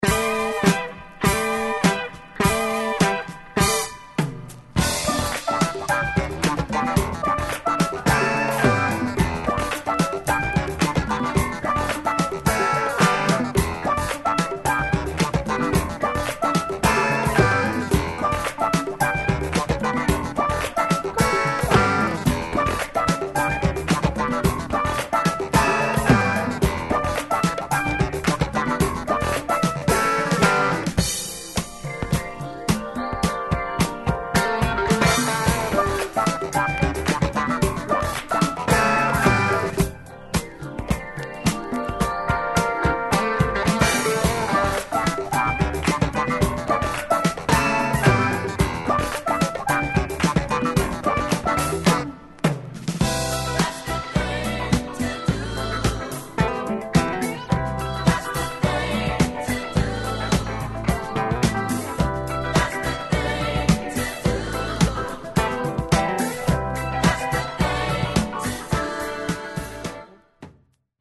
タイプの極上Modern Soul